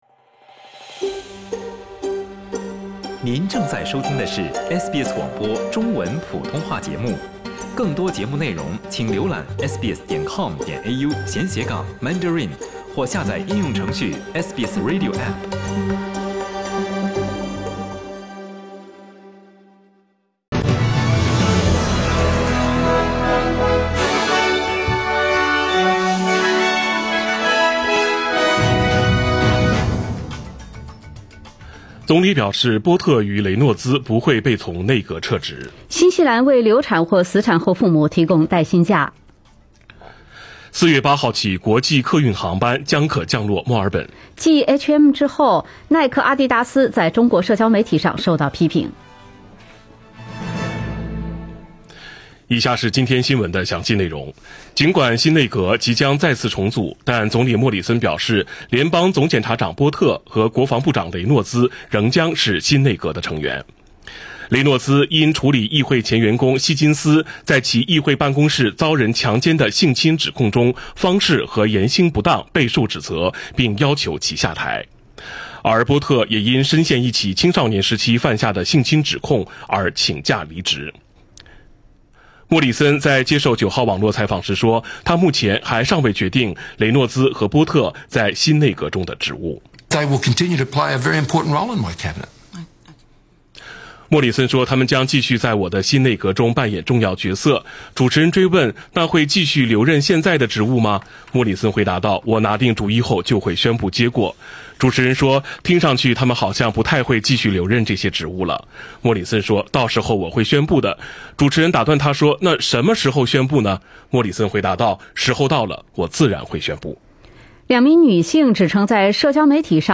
SBS早新聞（3月26日）